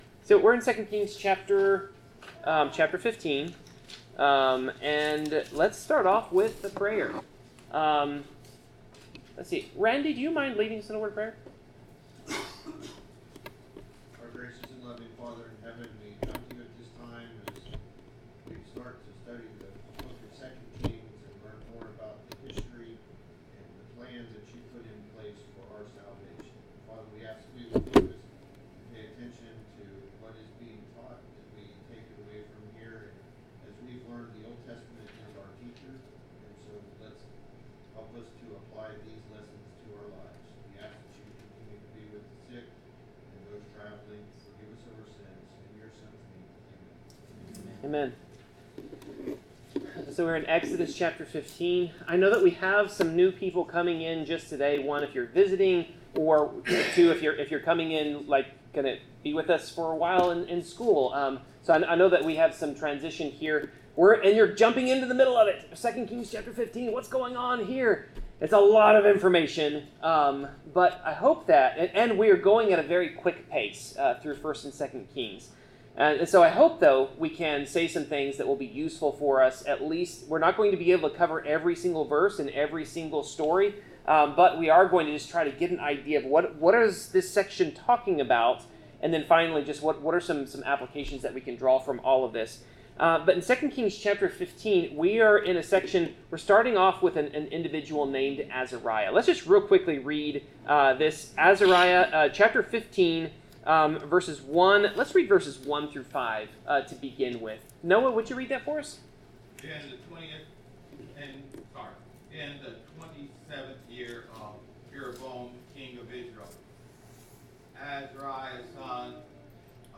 Bible class: 2 Kings 15-16
Service Type: Bible Class